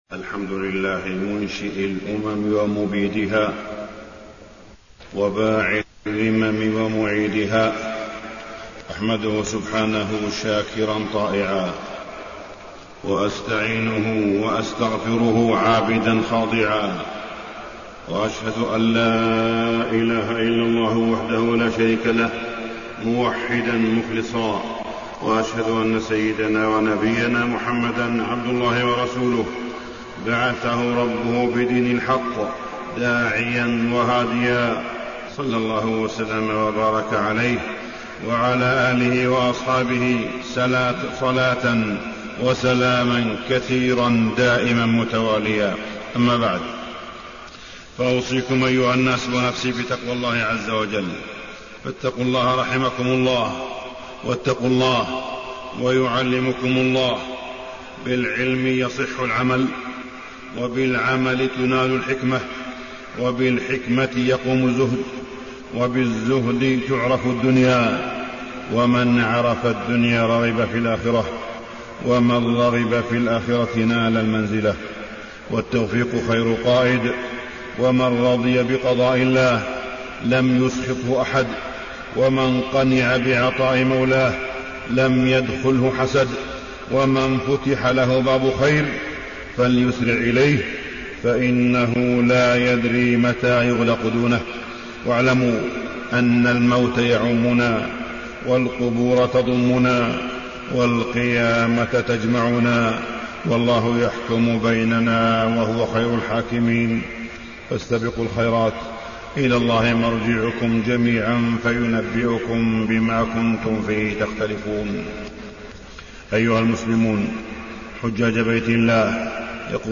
تاريخ النشر ٢٣ ذو القعدة ١٤٣٢ هـ المكان: المسجد الحرام الشيخ: معالي الشيخ أ.د. صالح بن عبدالله بن حميد معالي الشيخ أ.د. صالح بن عبدالله بن حميد الإسلام دين الأخلاق